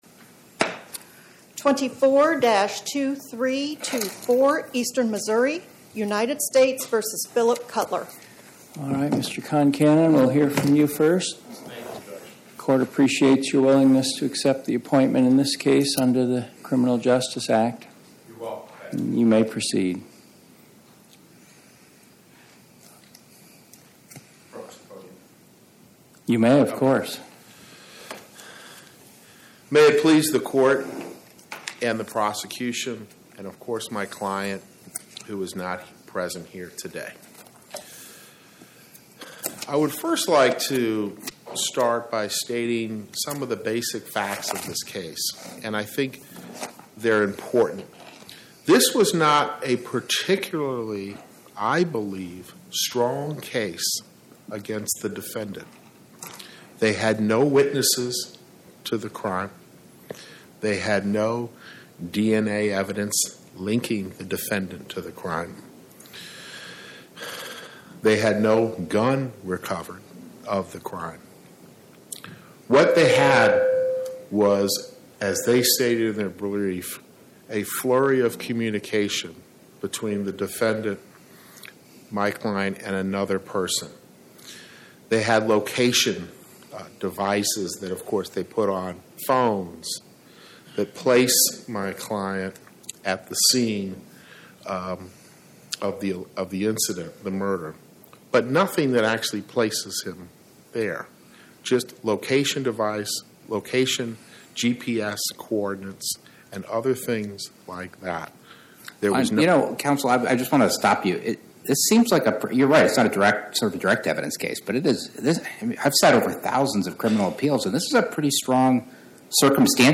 Oral argument argued before the Eighth Circuit U.S. Court of Appeals on or about 09/19/2025